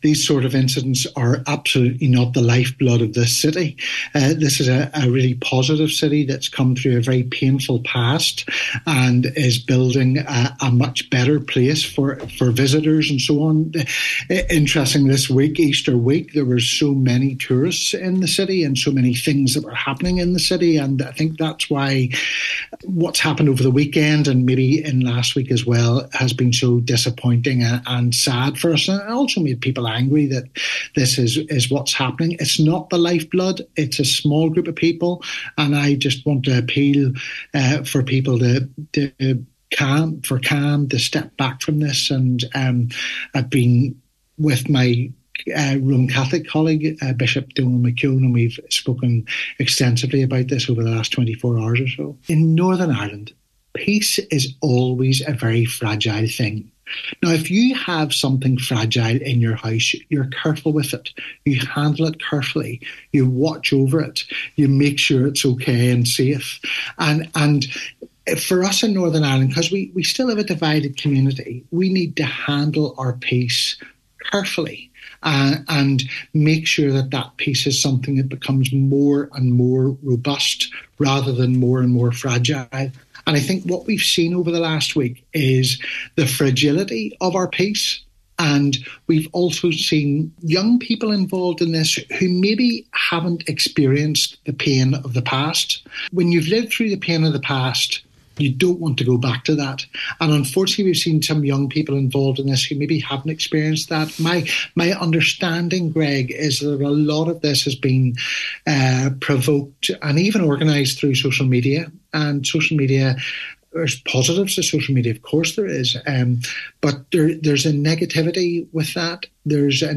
On today’s Nine til Noon Show, Church of Ireland Bishop Andrew Forster called for calm, saying these incidents do not represent the lifeblood of the city.